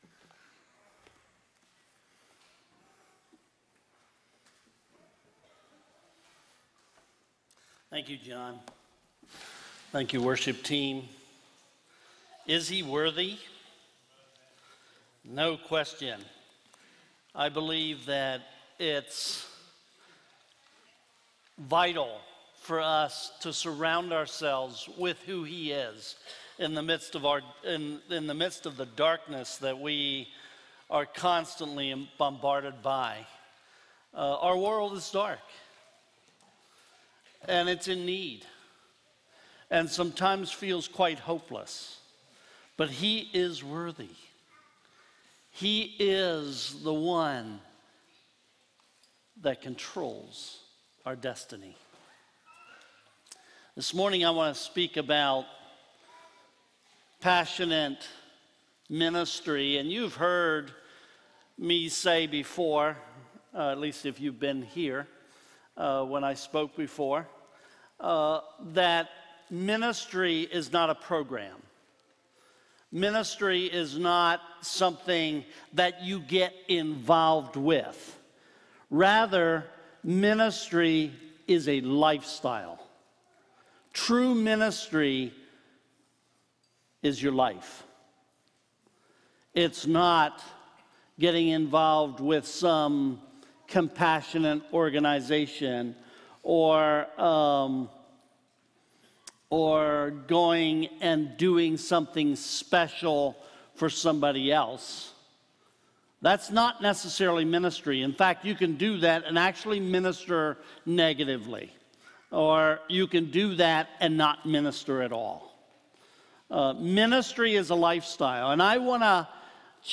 Sermon and Missionary Update